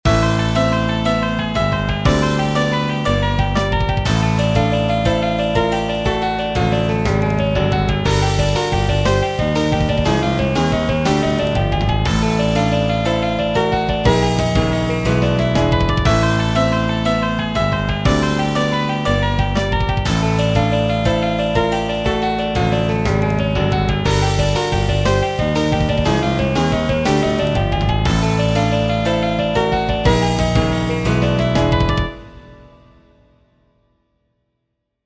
Short 120bpm loop in 17edo
17edo_demo.mp3